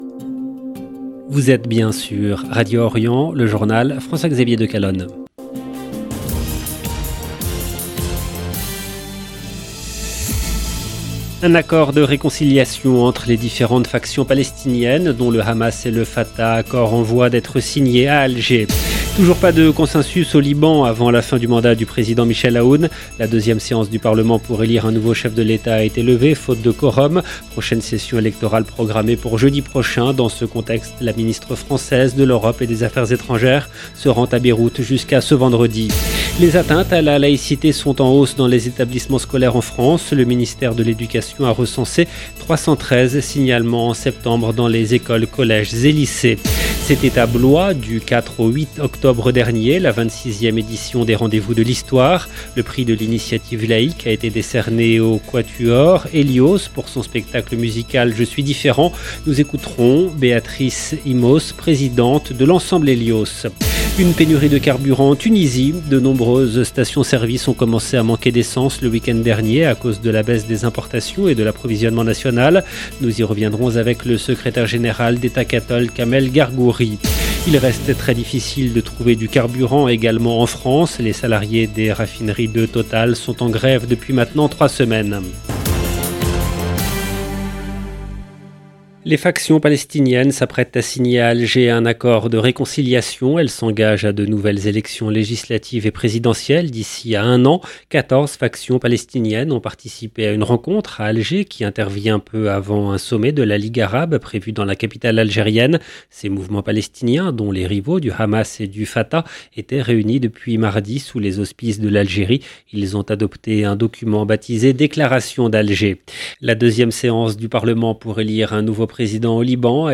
LE JOURNAL DU SOIR EN LANGUE FRANCAISE DU 13/10/22